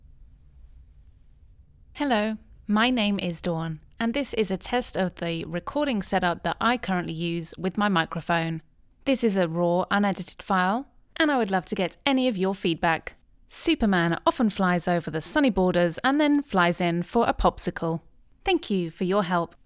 I am recording podcasts and I notice a lot of ssss in words like systems.
Does anyone have an idea of the settings to use like frequency and attack or release for a female voice recording.
I will send you a sample of unedited recording.
I have two mics. One is a blue spark xlr and the other is an Oktava 319, both run through an apogee one interface.